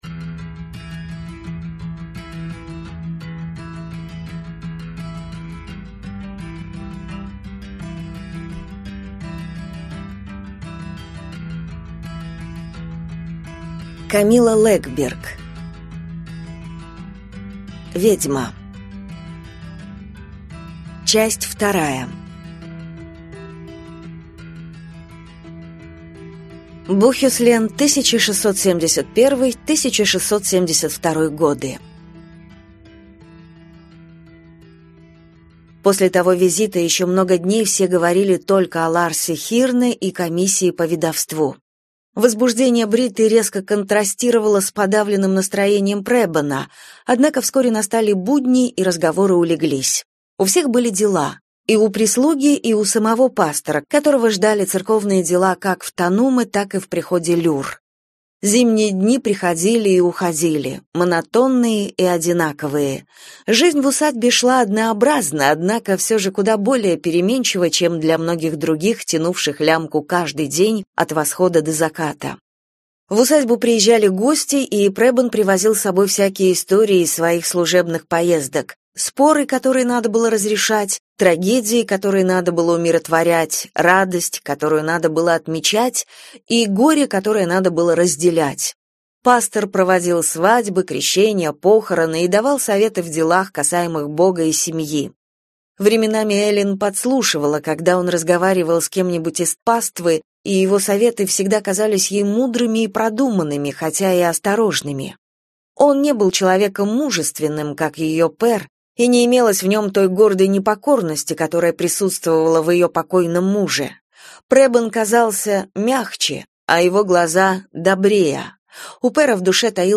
Аудиокнига Ведьма. Часть 2 | Библиотека аудиокниг